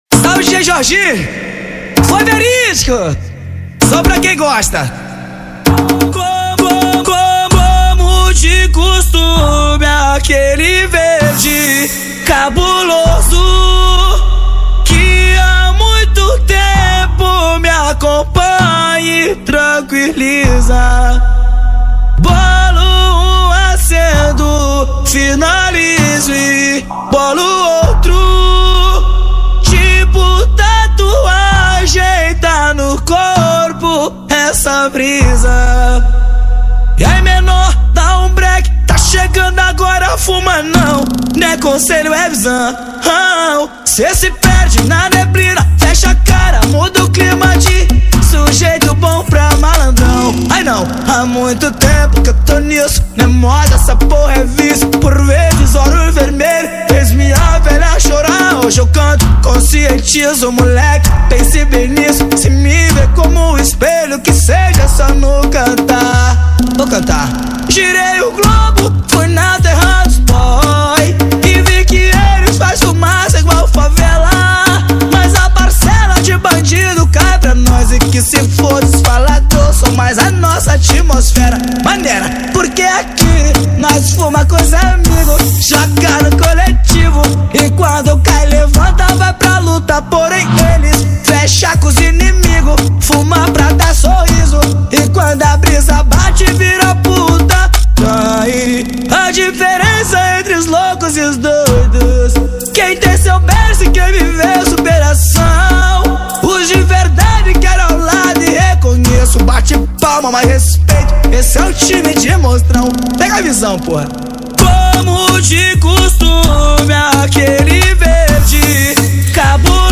2024-12-21 23:48:48 Gênero: Funk Views